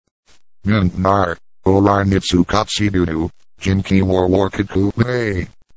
入力した英単語をやたらごつい声で話してくれるすばらしい機能です
ちなみに、『mint nar! oh rar nit su cot see do to? gin key war wark it qoo lay!』と言っています。